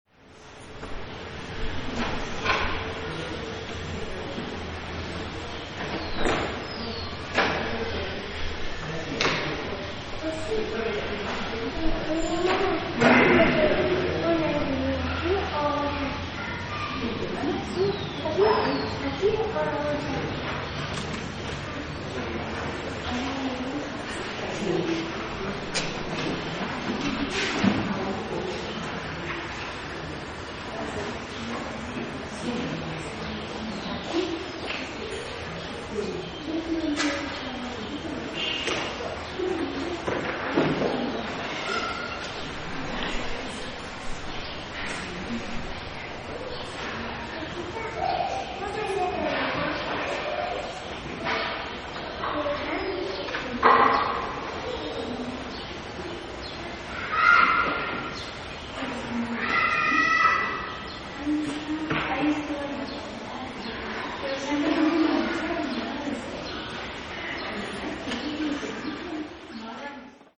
Despues de escuchar la música de marimba en la plazuela, decidimos entrar a un remanso silencioso que nos invitaba a quedarnos un rato sentados en una de sus bancas; es la iglesia del Cerrillo en San Cristóbal de Las Casas, dedicada a La Transfiguración del Señor, data del siglo XVII.